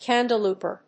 アクセント・音節cándle・pòwer